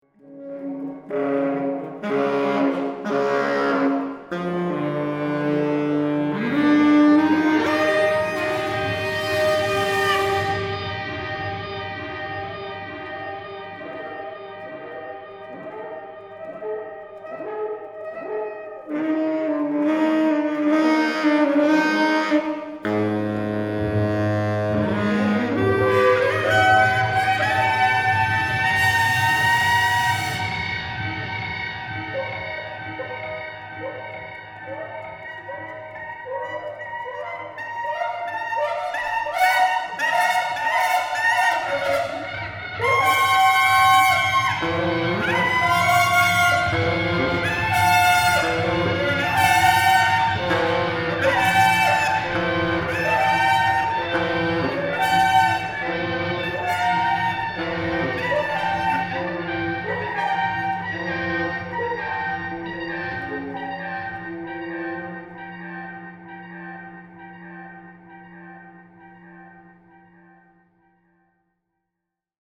für Tenorsaxophon, Horn und LoFi-Elektronik